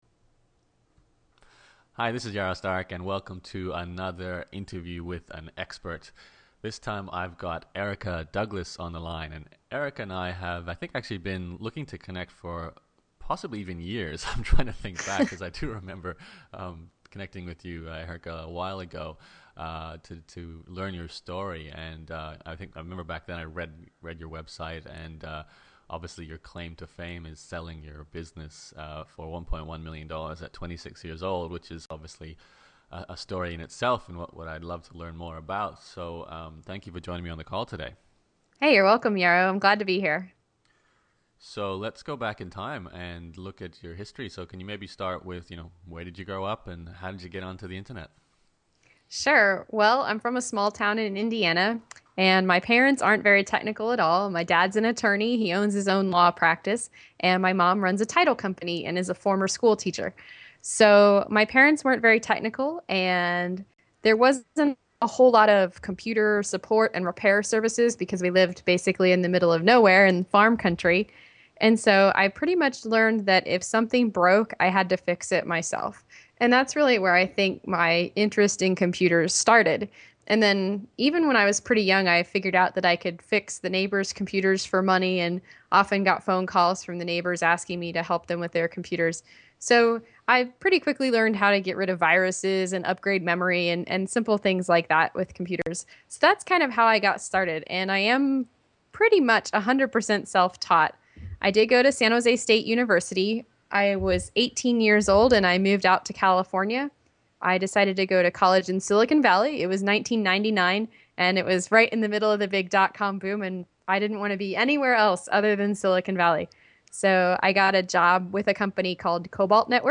In the end good customer service and relationships made the difference. If you’re interested in running a web services company, this is definitely a podcast interview worth listening.